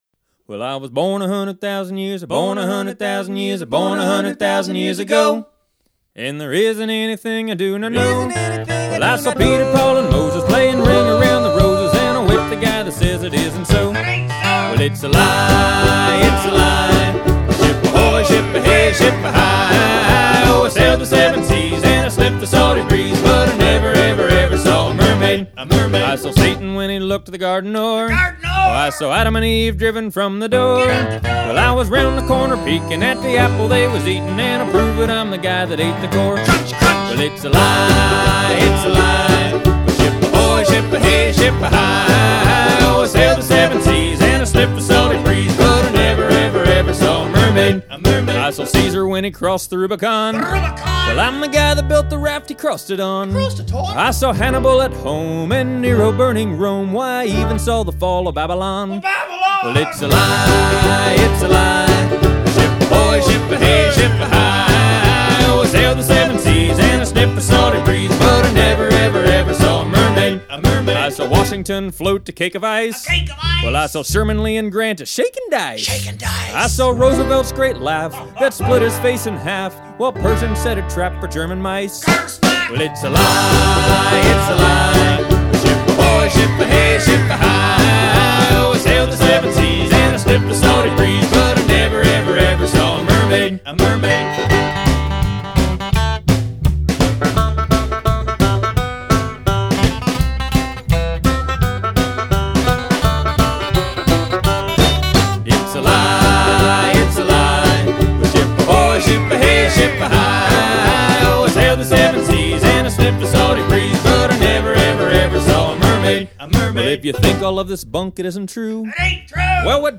harmonica
fiddle